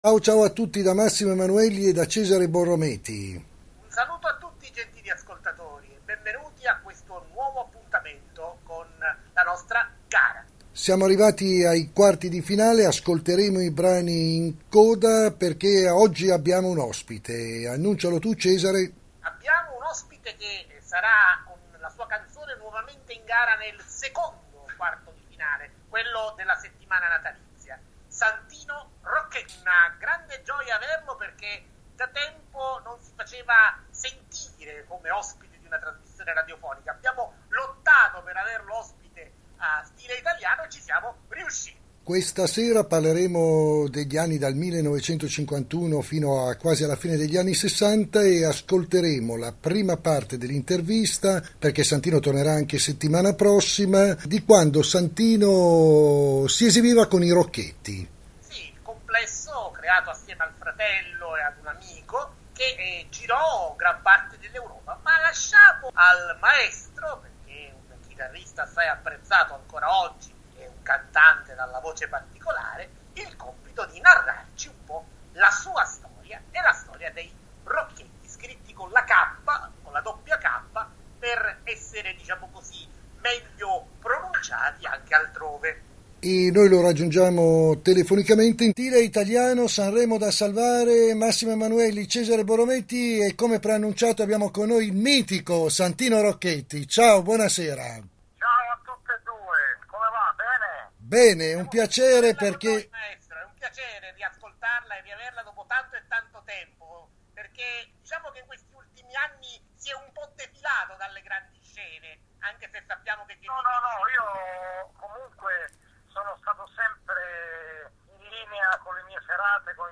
Il podcast intervista a Santino Rocchetti
santino-rocchetti-solo-parlato.mp3